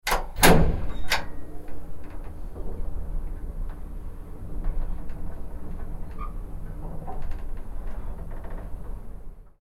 Vintage 1970s Elevator Sound Effect – Button Press, Door, Movement
Authentic 1970s elevator interior sound effect. Captures the tactile press of a mechanical plastic button, the gentle creak of the cabin as it moves, and the subtle ambient hum of the elevator in operation. Ends as the doors open at the destination floor.
Vintage-1970s-elevator-sound-effect-button-press-door-movement.mp3